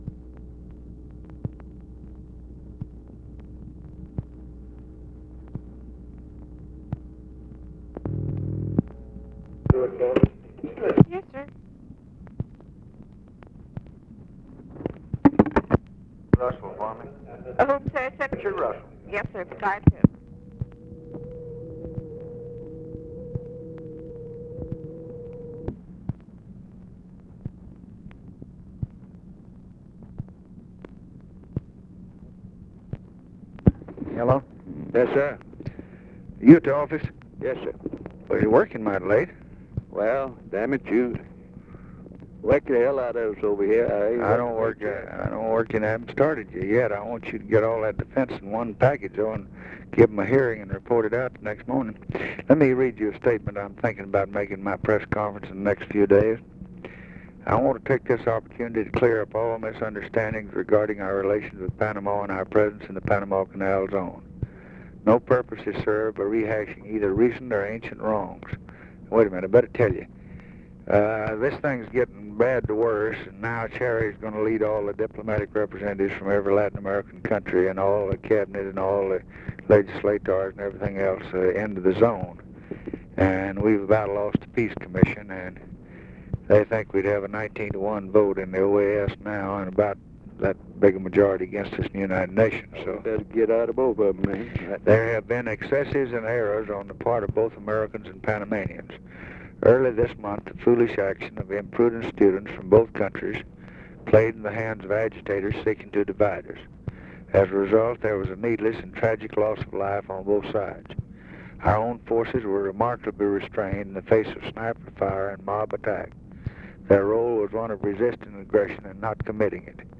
Conversation with RICHARD RUSSELL, January 22, 1964
Secret White House Tapes